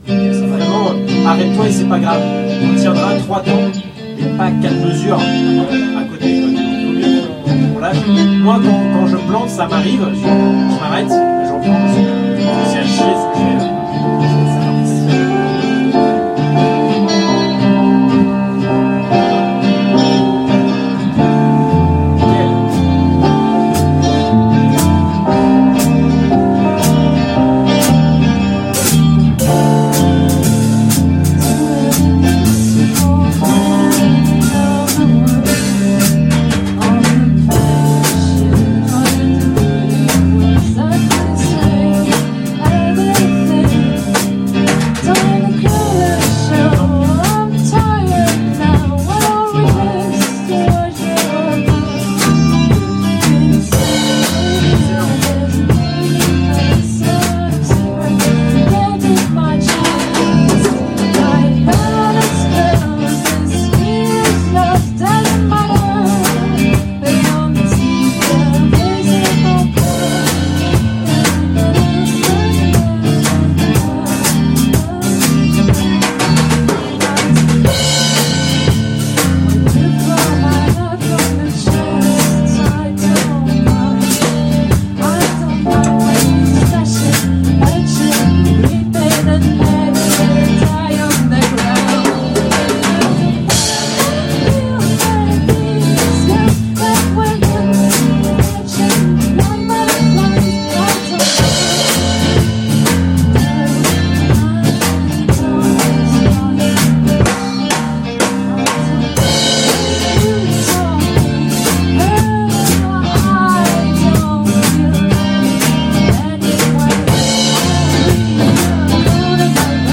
Impro
Walking Bass